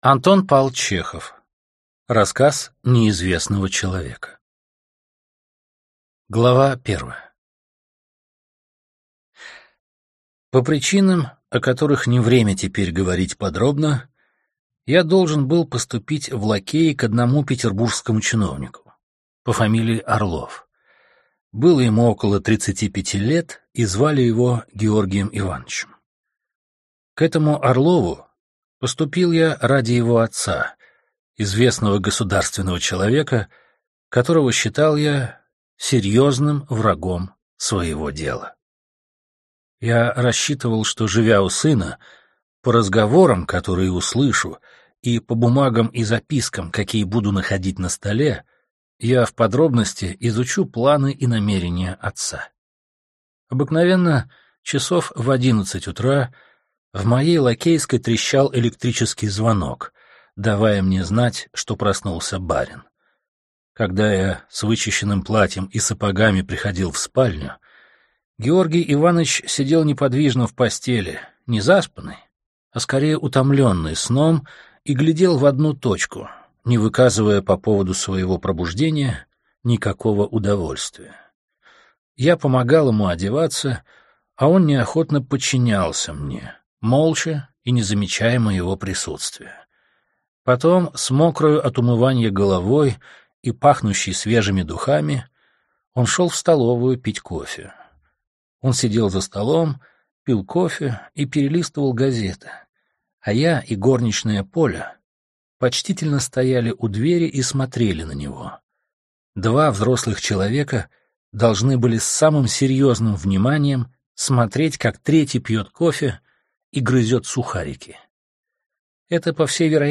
Аудиокнига Рассказ неизвестного человека | Библиотека аудиокниг